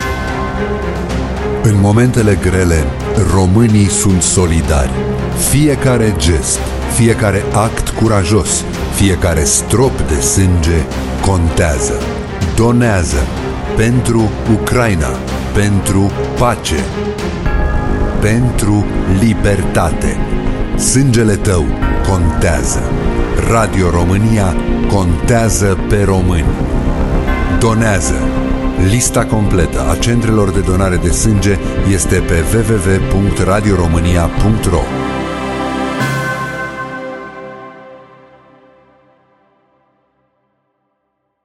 Spotul audio dedicat acestei campanii: